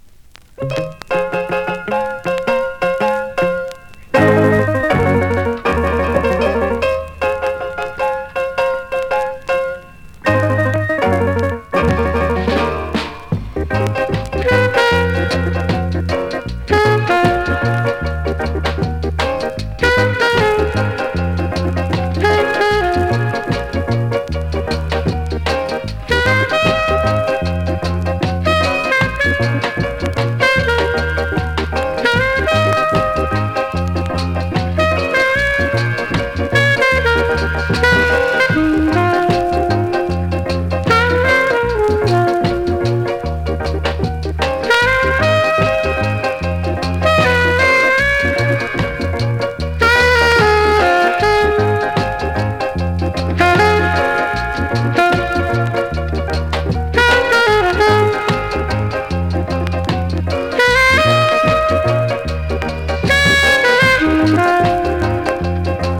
※前半〜中盤にしばらくジリノイズ有
コメントMEGA RARE ROCKSTEADY!!